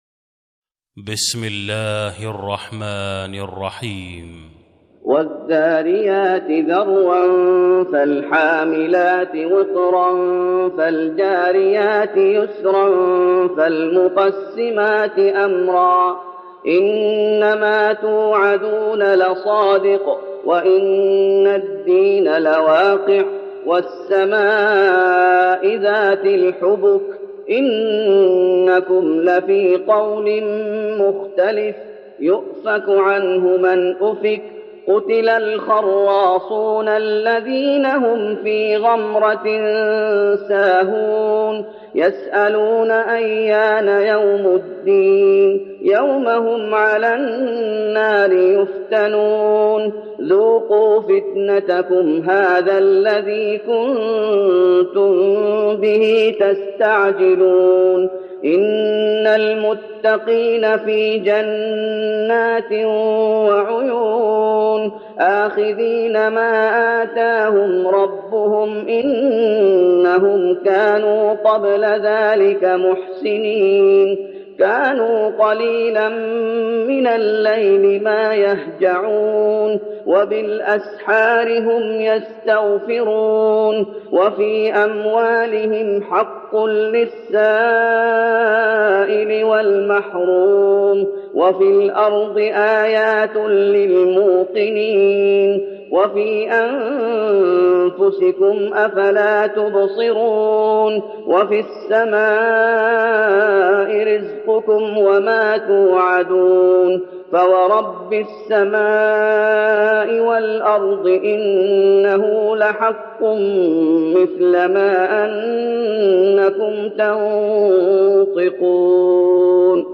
تراويح رمضان 1412هـ من سورة الذاريات(1-23) Taraweeh Ramadan 1412H from Surah Adh-Dhaariyat > تراويح الشيخ محمد أيوب بالنبوي 1412 🕌 > التراويح - تلاوات الحرمين